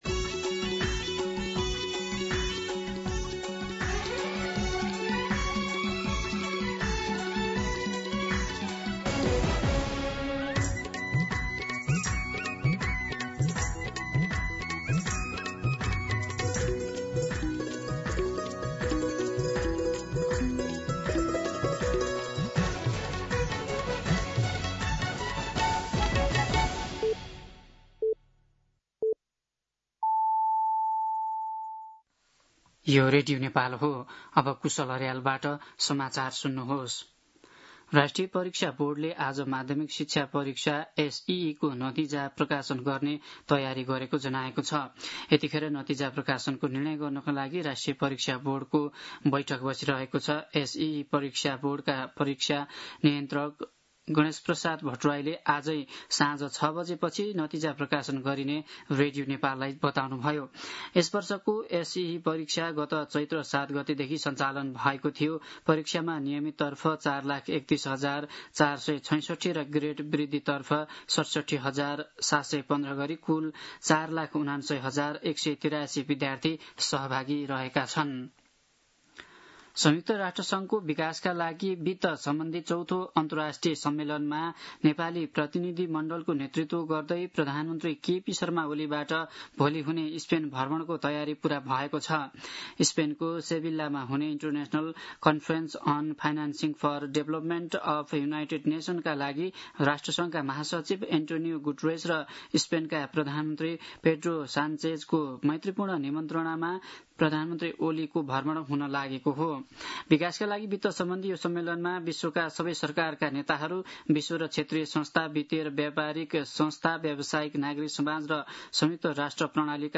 दिउँसो ४ बजेको नेपाली समाचार : १३ असार , २०८२
4pm-News-13.mp3